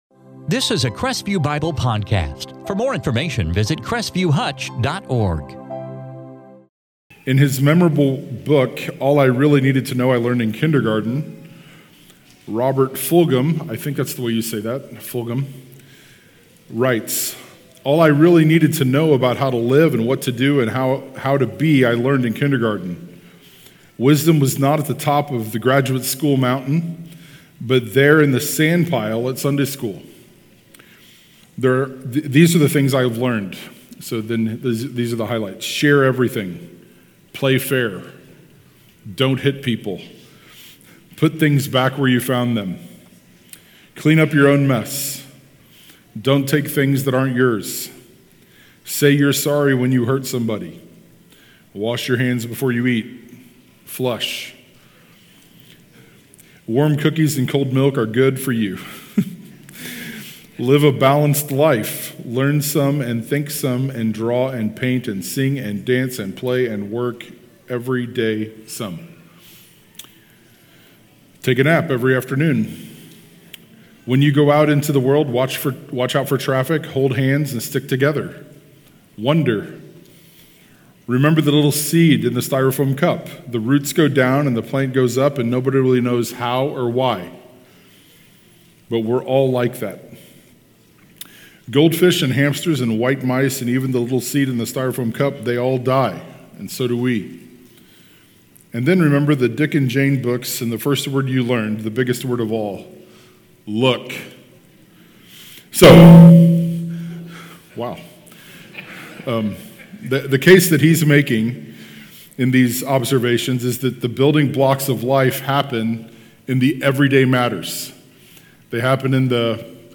In this sermon on family discipleship from Psalm 78:5-8